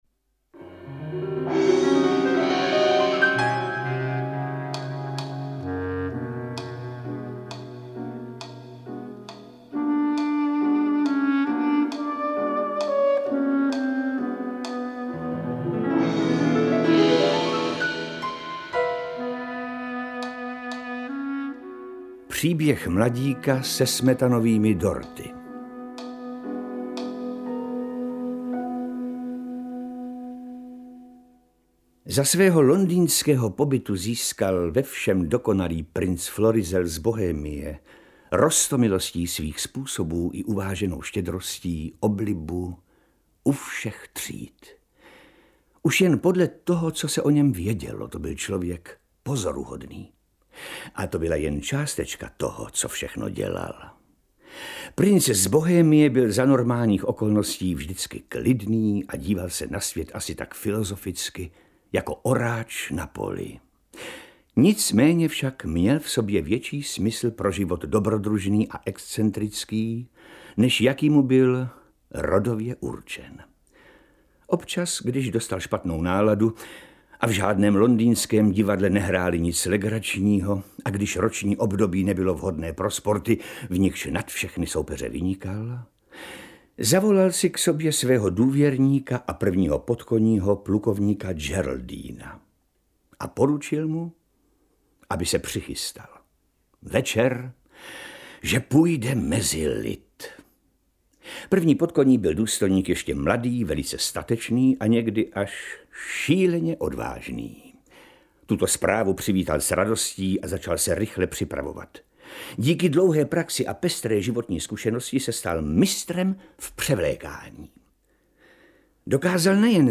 Interpreti:  Vladimír Dlouhý, Petr Kostka